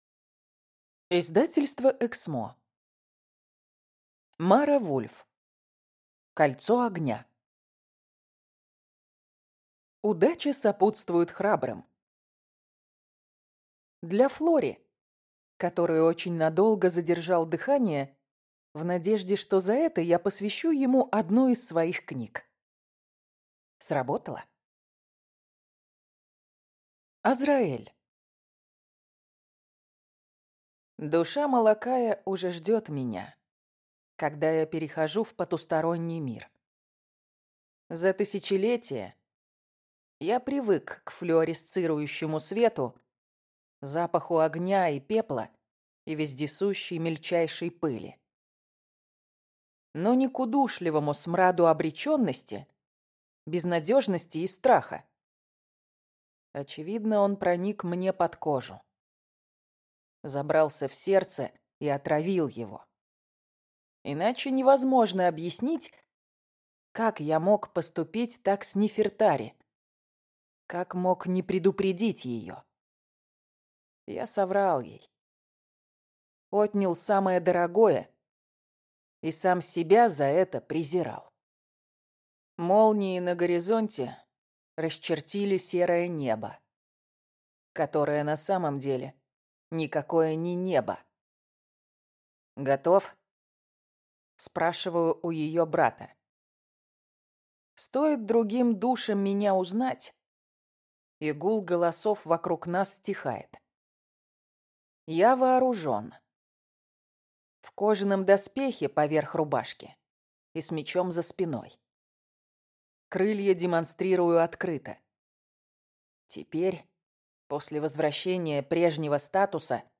Аудиокнига Египетские хроники. Кольцо огня | Библиотека аудиокниг